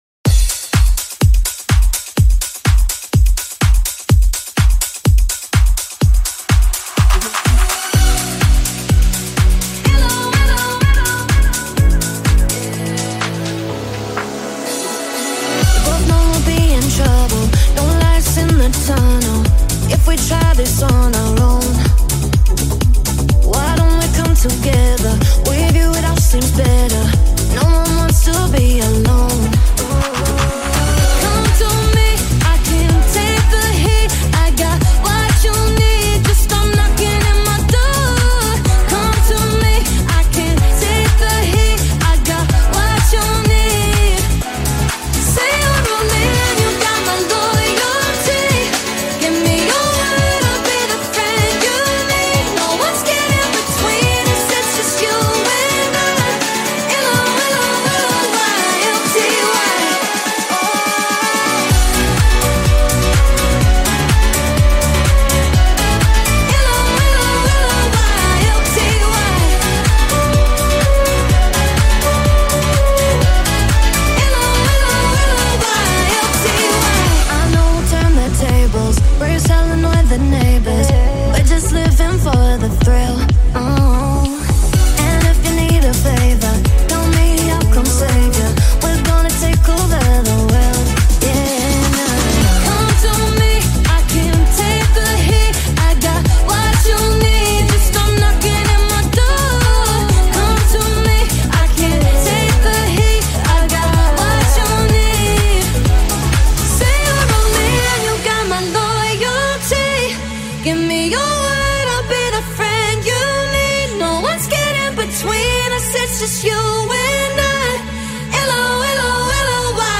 High quality Sri Lankan remix MP3 (3.5).
mixtape